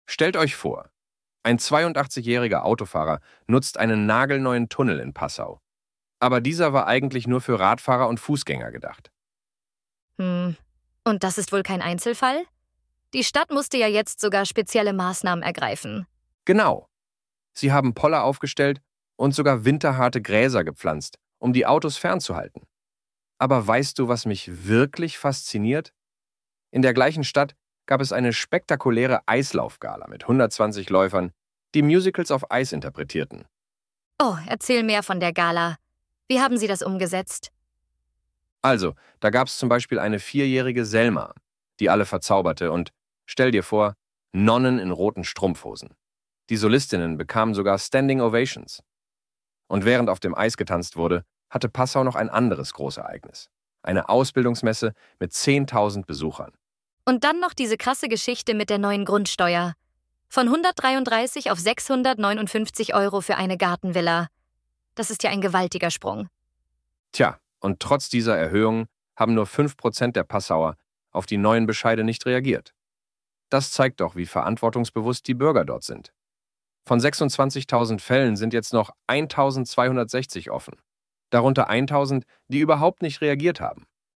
Unser Nachrichtenticker als KI-Kost: „Dialog aus der Dose“ –